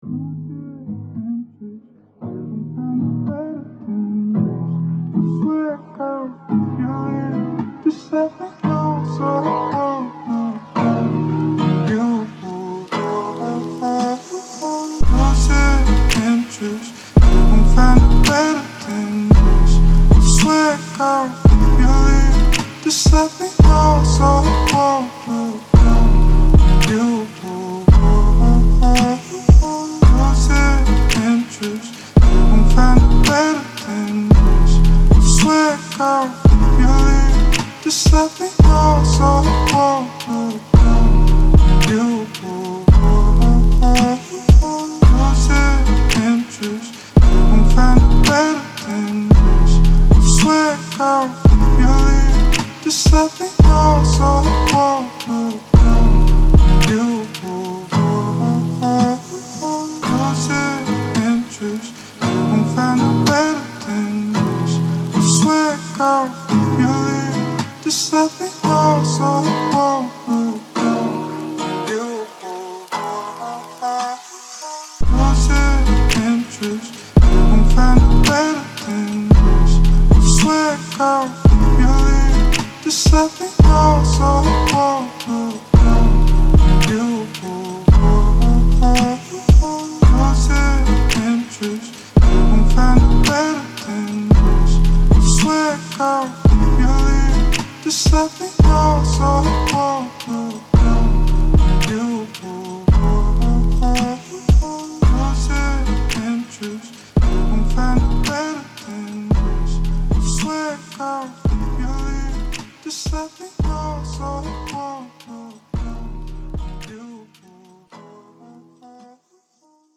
سبک ریمیکس